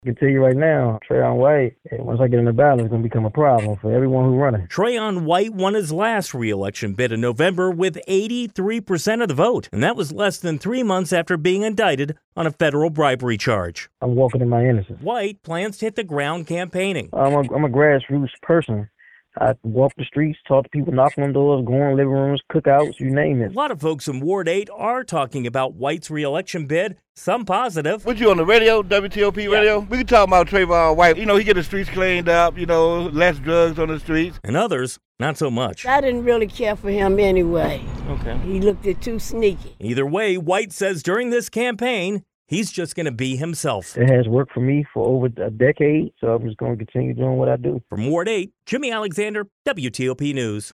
In an exclusive interview with WTOP, White said he had filed the paperwork on Monday to be on the July special election ballot.